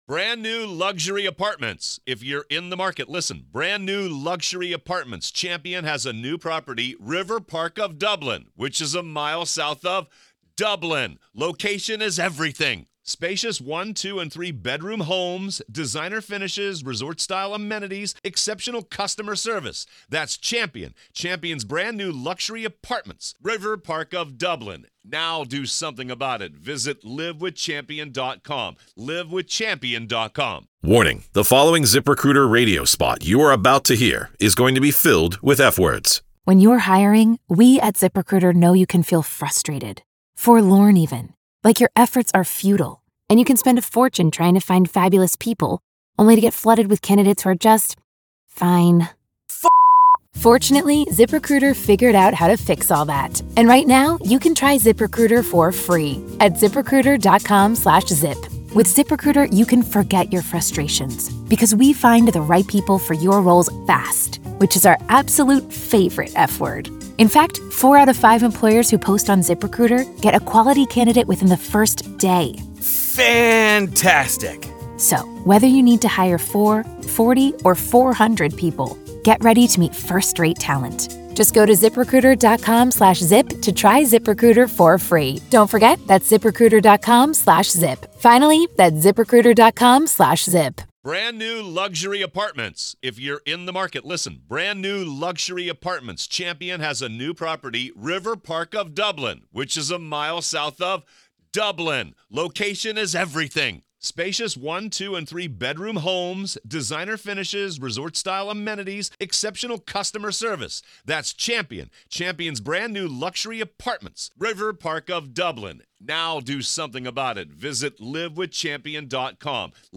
True Crime Today | Daily True Crime News & Interviews / Is It Impossible At This Point For A Fair Trial Against Karen Read?